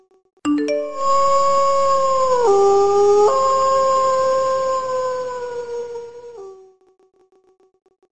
Nada notifikasi Suara Serigala
Kategori: Nada dering
🔥 Nada notifikasi suara serigala ini cocok banget buat kamu yang suka suasana misterius dan keren.
nada-notifikasi-suara-serigala-id-www_tiengdong_com.mp3